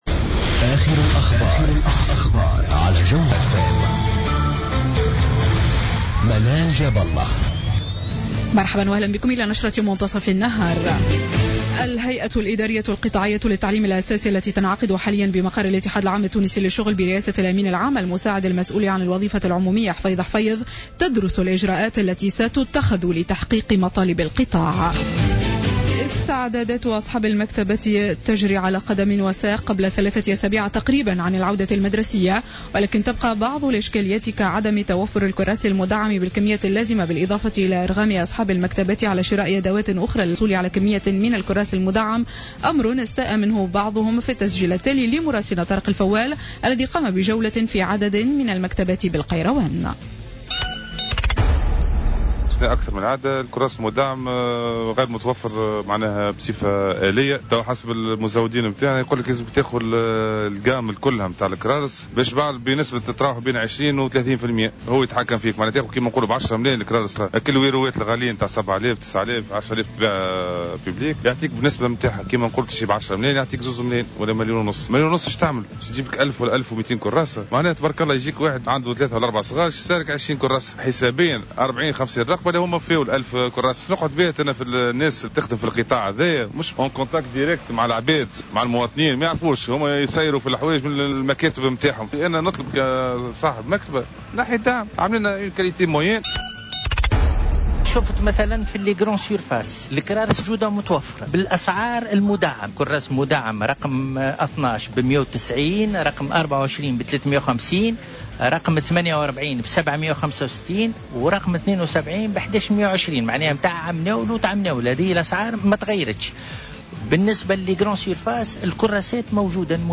نشرة أخبار منتصف النهار ليوم السبت 22 اوت 2015